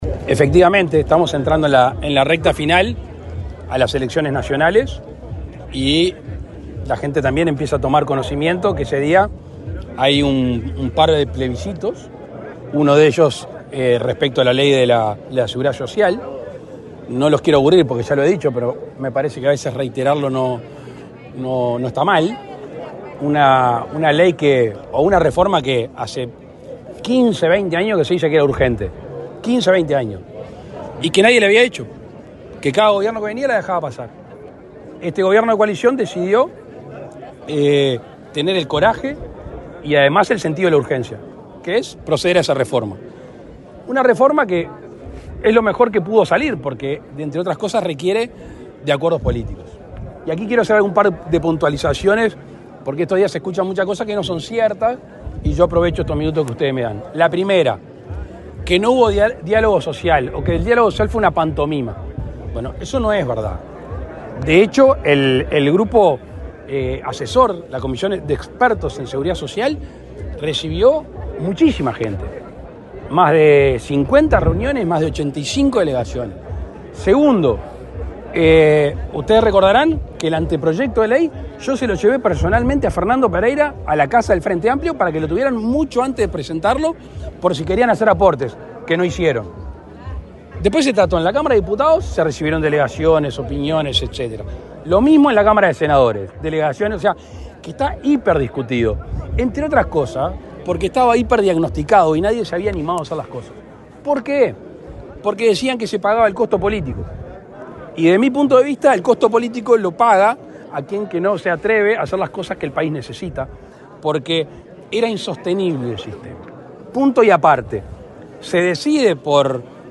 Declaraciones del presidente Luis Lacalle Pou
Declaraciones del presidente Luis Lacalle Pou 15/10/2024 Compartir Facebook X Copiar enlace WhatsApp LinkedIn El presidente de la República, Luis Lacalle Pou, participó, este martes 15, en la inauguración del aeropuerto internacional de Melo, en el departamento de Cerro Largo. Luego, dialogó con la prensa.